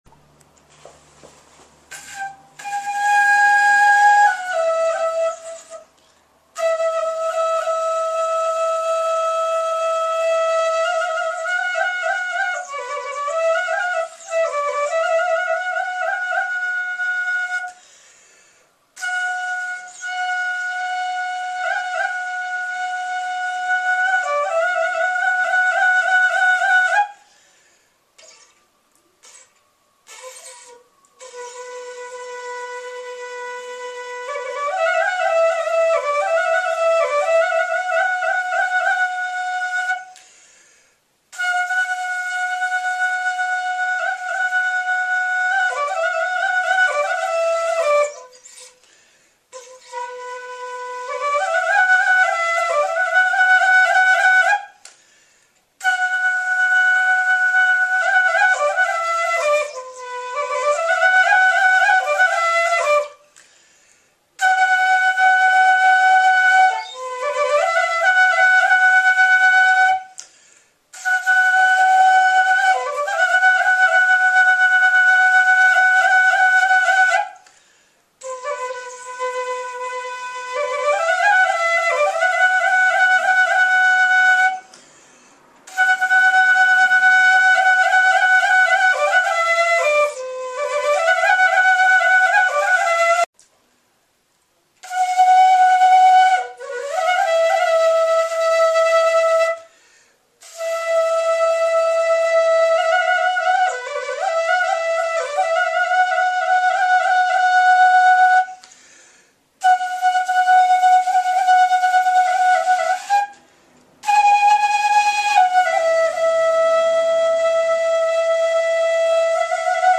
Keywords: Tibet; flute; music;
Abstract: Songs, music, and stories from Rka phug Tibetan Village, Khams ra Town, Gcan tsa County, Huangnan Tibetan Autonomous Prefecture, Qinghai Province, PR China.